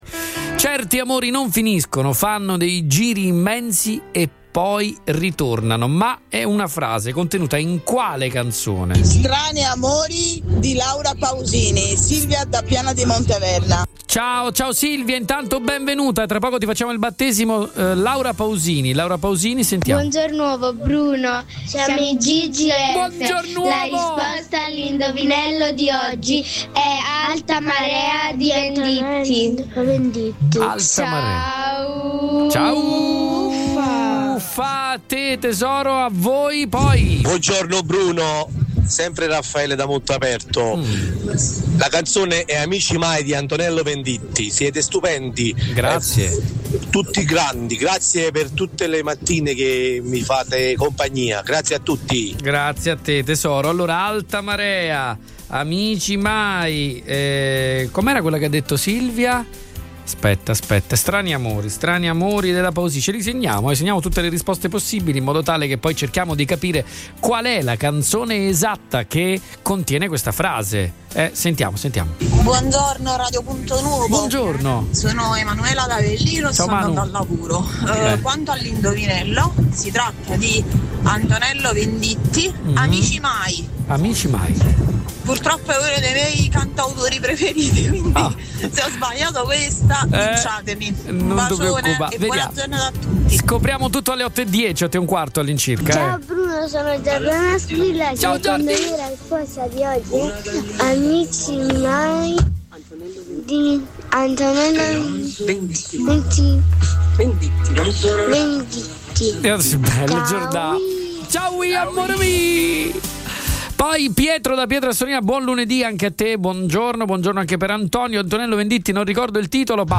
RIASCOLTA DA QUI LE RISPOSTE DEGLI ASCOLTATORI